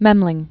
(mĕmlĭng) also Mem·linc (-lĭngk), Hans 1430?-1494.